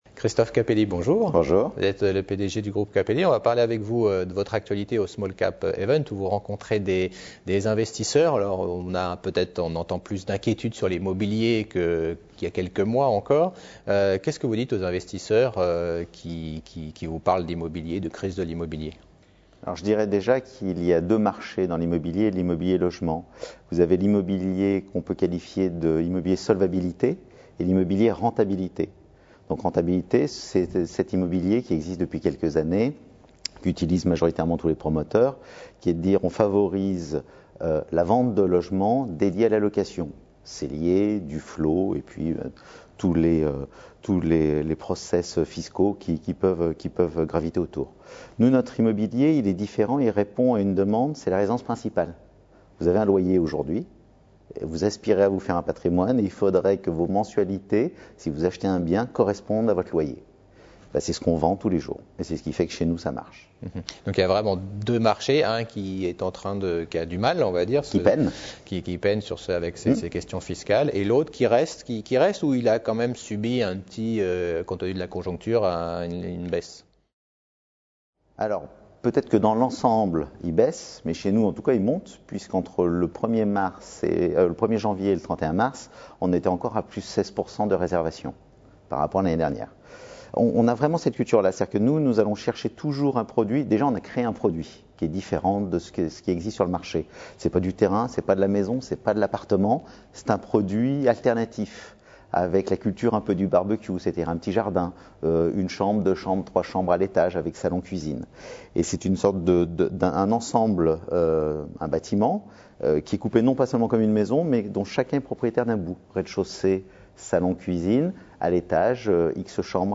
Smallcap Event 2013